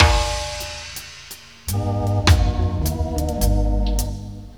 DUBLOOP 08-R.wav